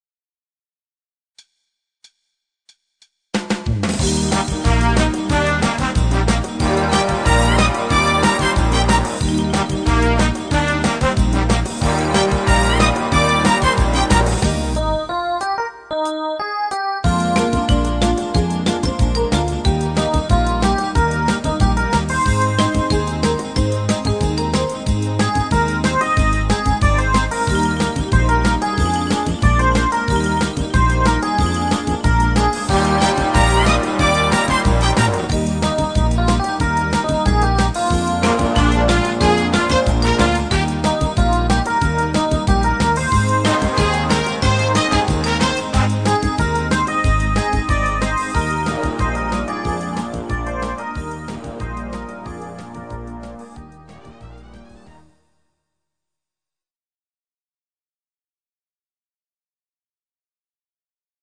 Genre(s): Schlager  |  Rhythmus-Style: Foxtrott